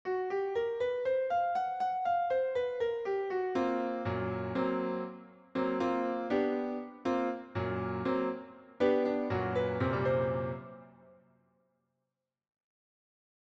Нотная запись в тональности F# блюз-минор
Блюз_минор.mp3